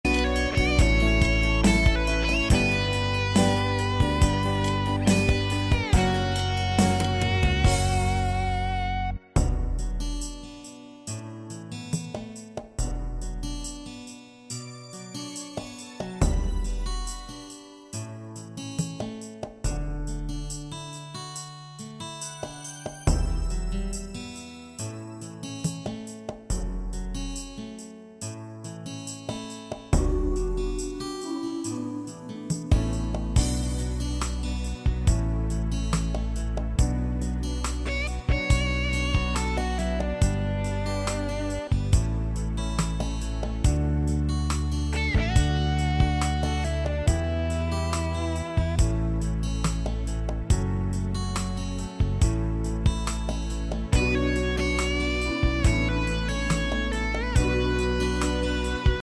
Nhạc Chế